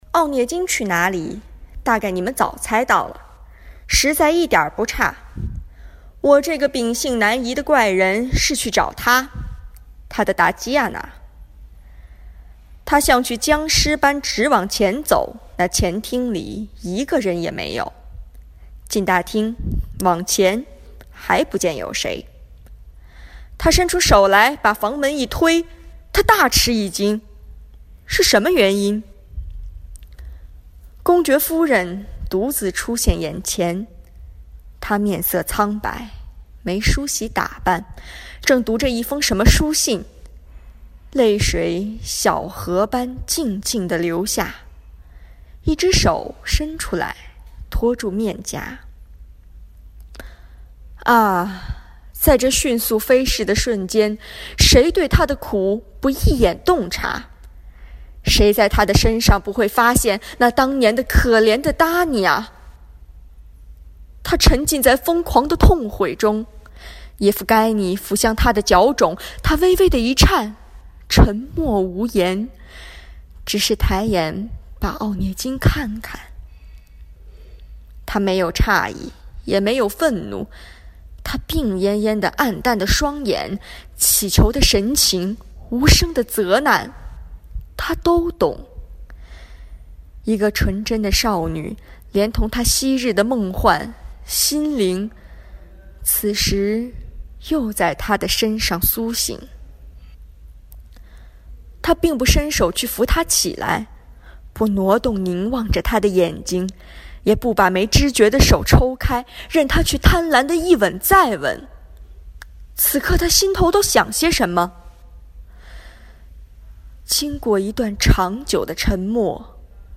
配音：叶甫盖尼·奥涅金.mp3